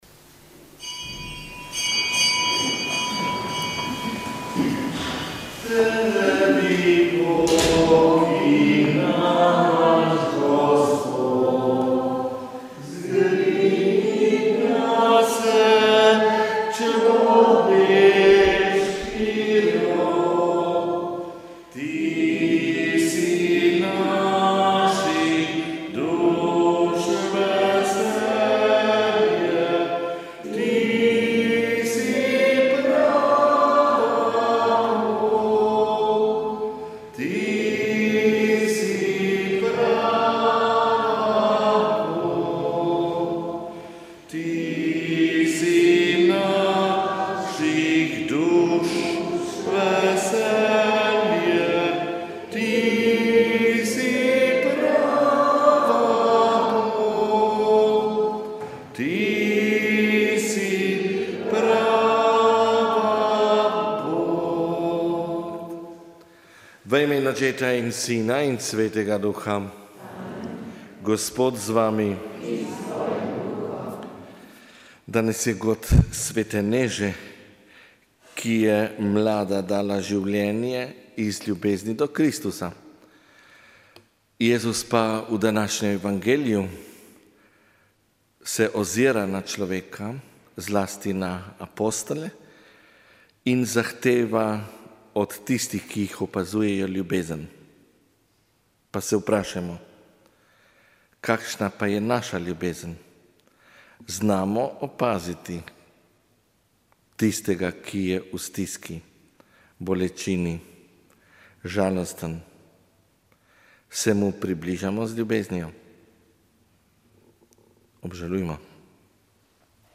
Sv. maša iz stolne cerkve sv. Janeza Krstnika v Mariboru 9. 9.